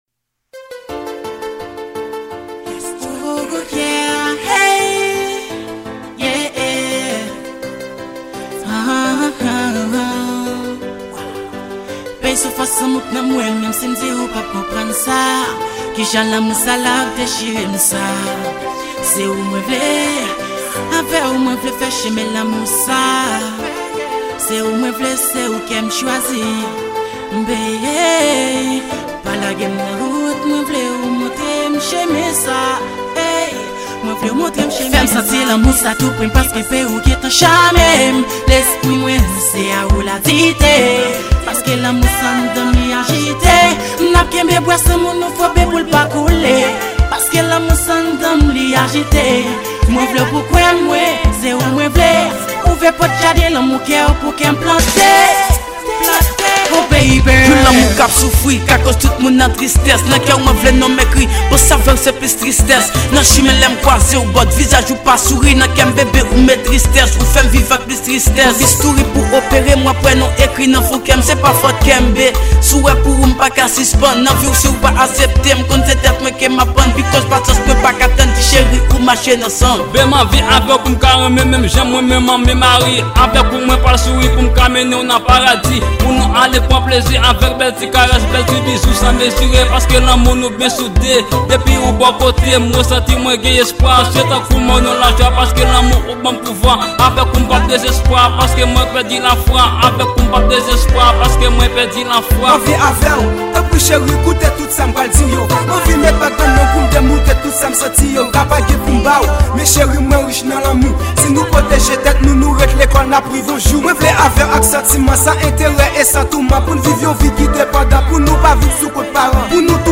Genre: rap.